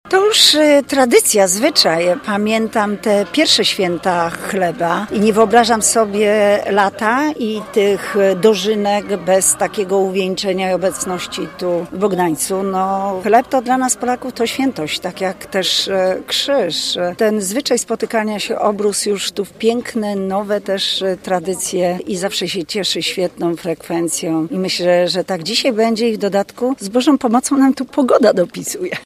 Dla obecnej na święcie europosłanki Elżbiety Rafalskiej, ta impreza to już tradycja.